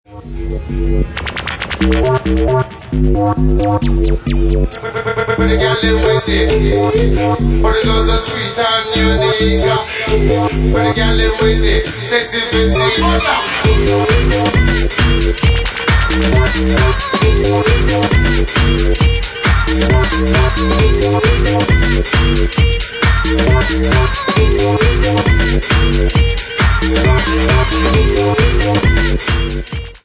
Genre:Bassline House
Bassline House at 132 bpm